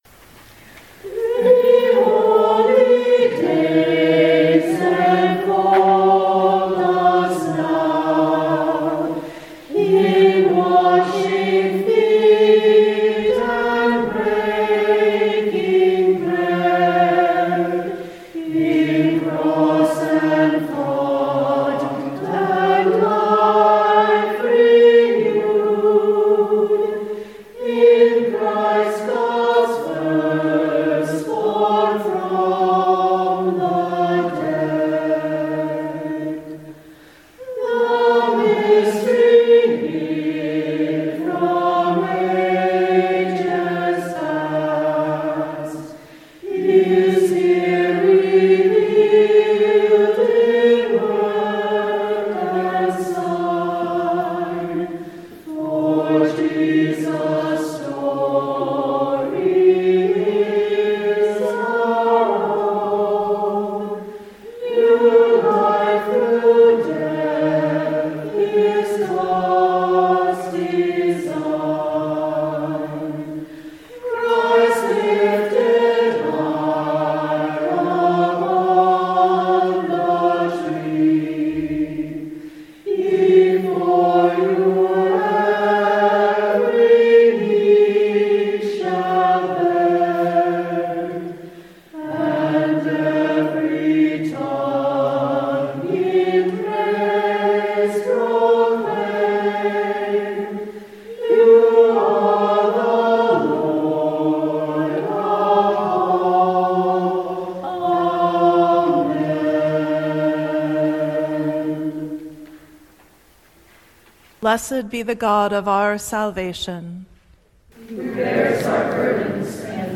Audio recording of the 7pm foot-washing service